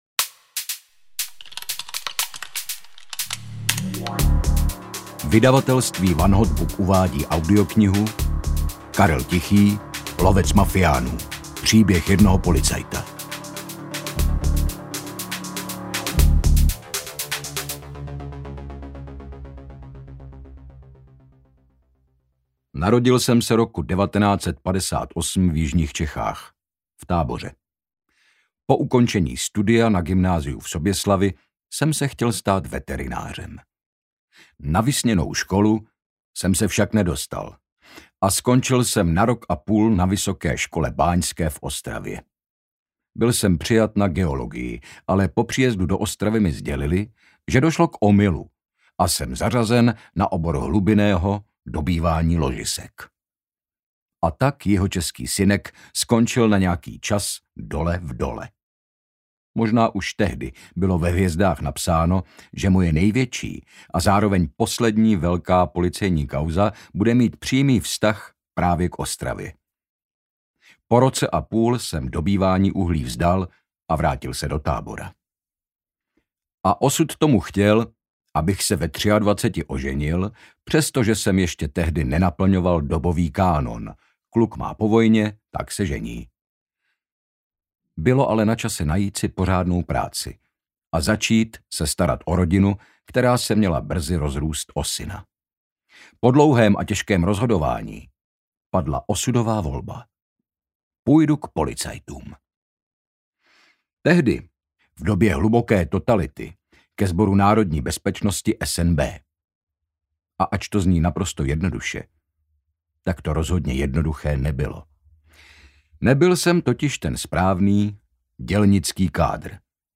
Lovec mafiánů audiokniha
Ukázka z knihy
• InterpretHynek Čermák
lovec-mafianu-audiokniha